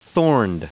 Prononciation du mot thorned en anglais (fichier audio)
Prononciation du mot : thorned